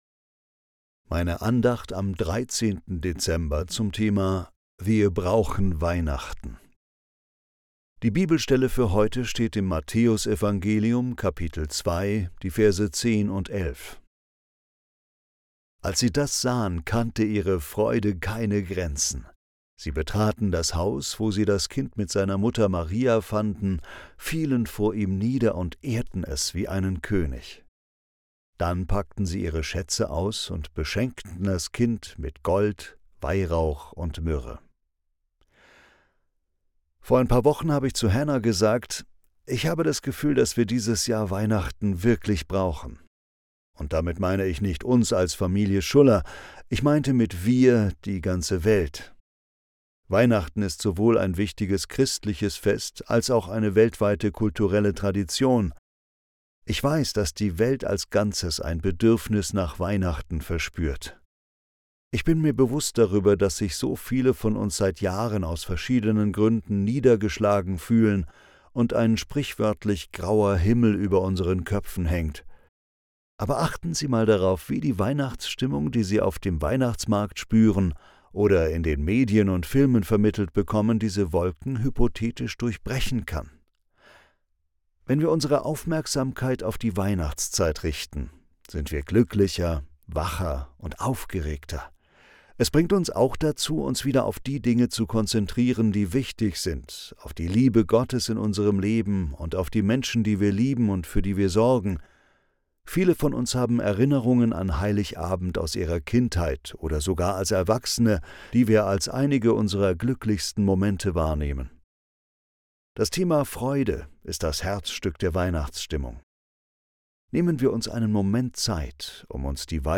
Andacht zum 13. Dezember